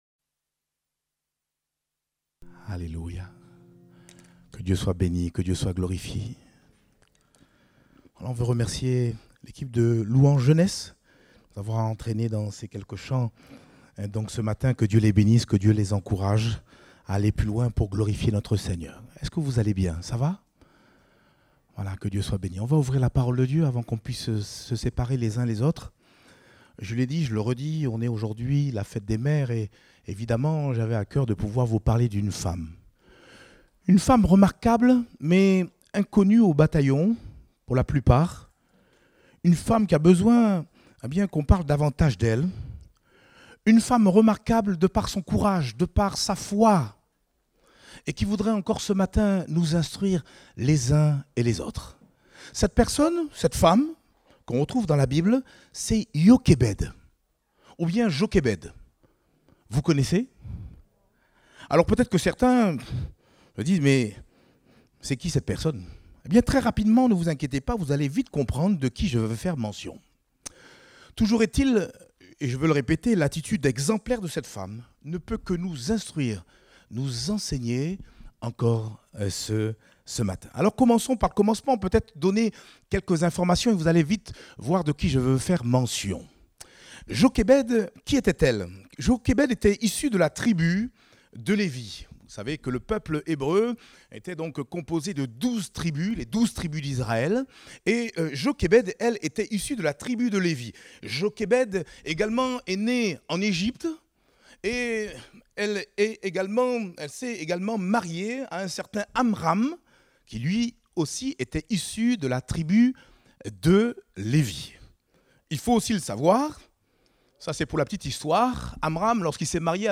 Date : 4 juin 2023 (Culte Dominical)